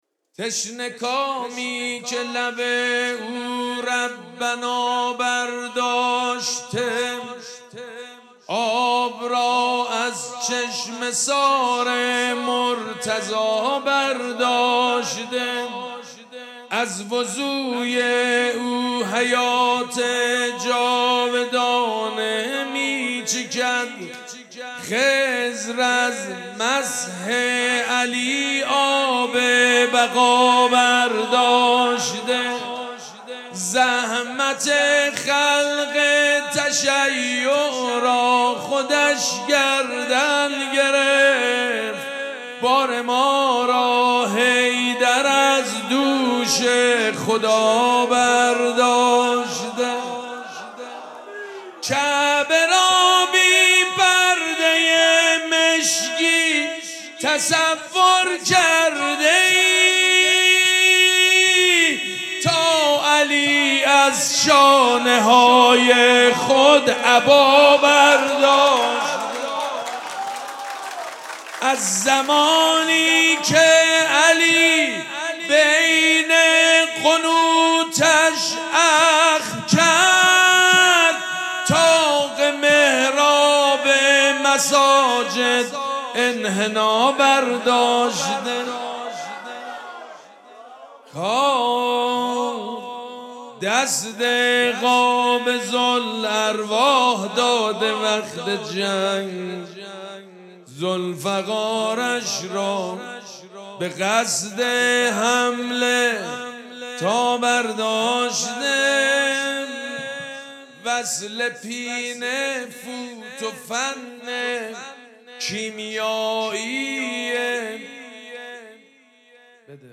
مراسم جشن ولادت حضرت زینب سلام‌الله‌علیها
حسینیه ریحانه الحسین سلام الله علیها
مدح
مداح
حاج سید مجید بنی فاطمه